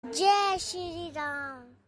Child Voice Ringtones
Message Tone Ringtones